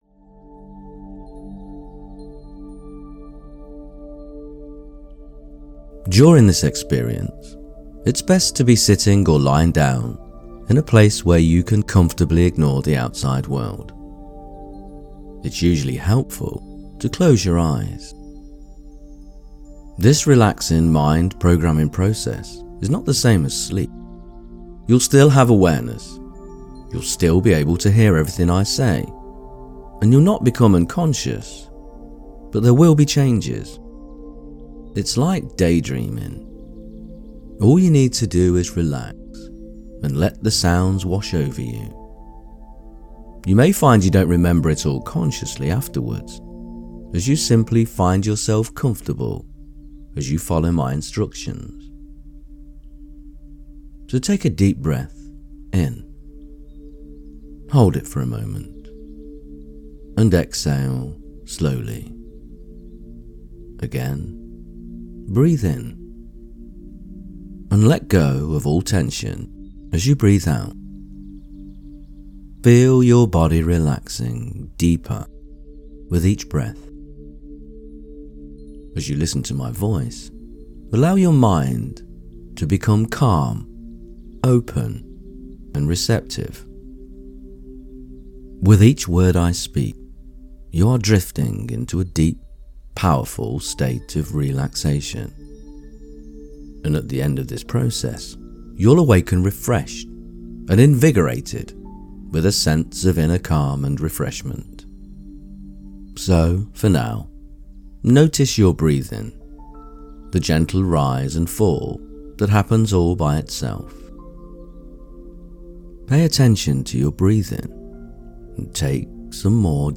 This guided meditation is your invitation to tune into the frequency of deep, soulful connection.
Loving-relationship-and-connection-guided-meditation-VM.mp3